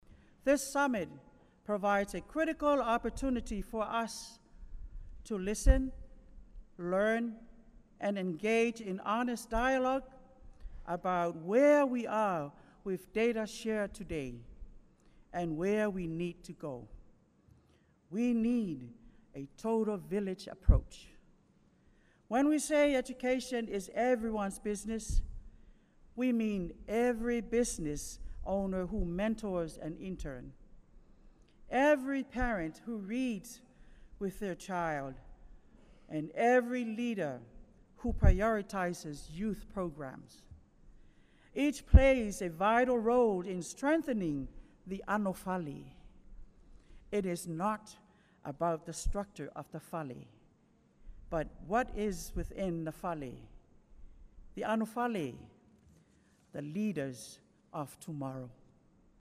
The Department of Education Summit on Public Education convened at the American Samoa Community College Multi-Purpose Center on Wednesday.
Director of Education Maefau Dr. Mary Taufetee spoke about the theme of the summit: Build the House with Many Posts. She said with all contributing dialogue and ideas, the territory can build a strong education system that will serve the students, our future leaders.